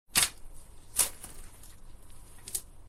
shovel4.ogg